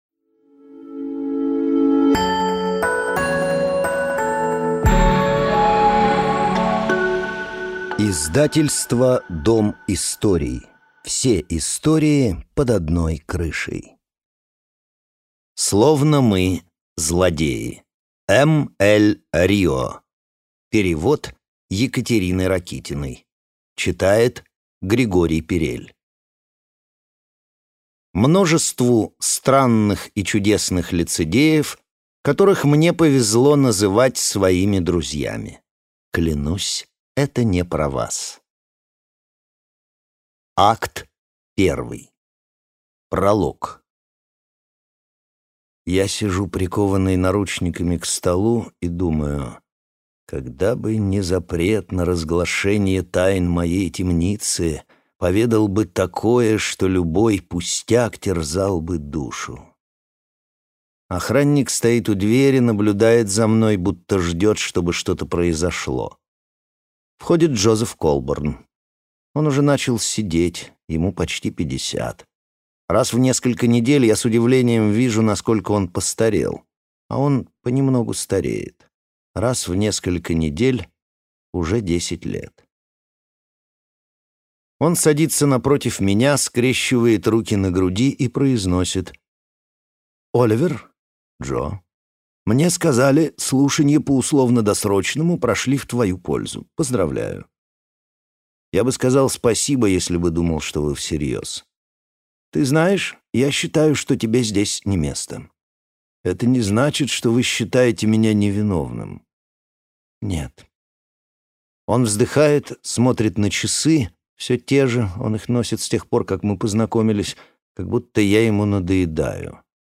Аудиокнига Словно мы злодеи | Библиотека аудиокниг